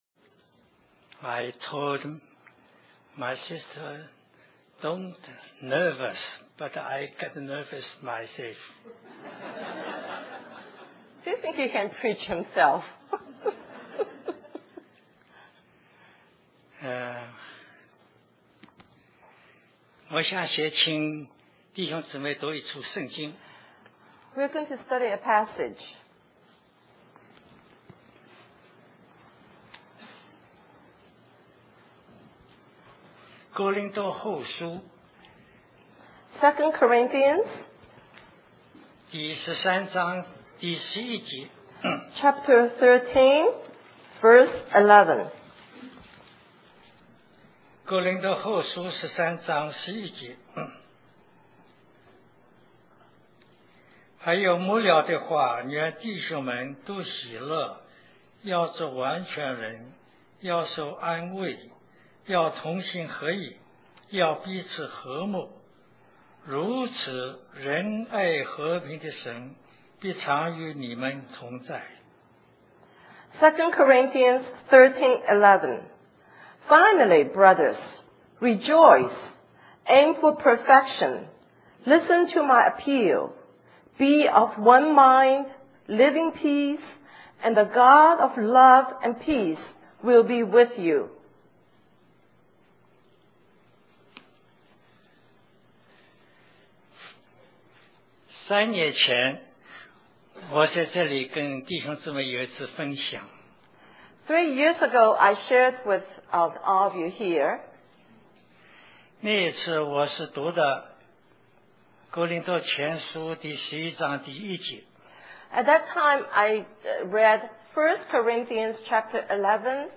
In this message he talks about living in the joy of the Lord despite our circumstances. The message was spoken in Chinese with English translation.